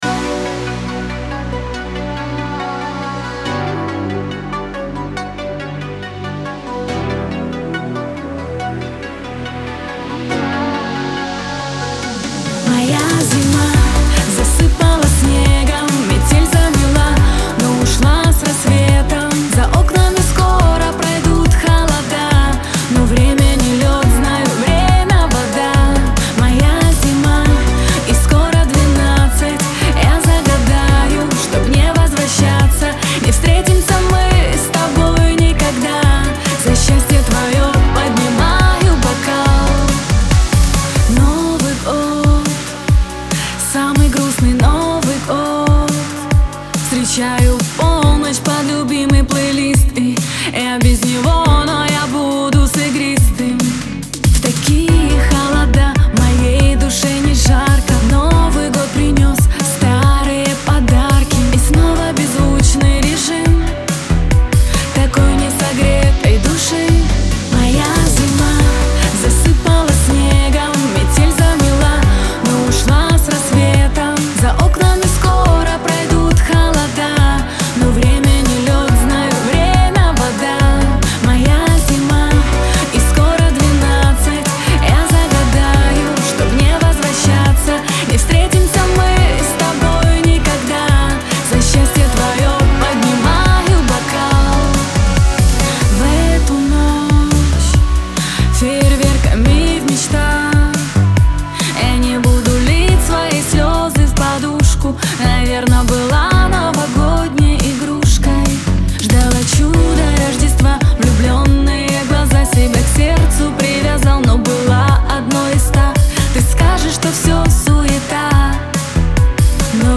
это трек в жанре поп
мелодичным звучанием
душевным вокалом